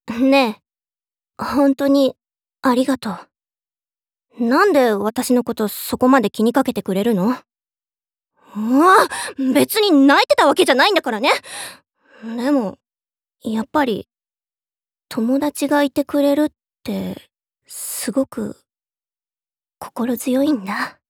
ボイスサンプル1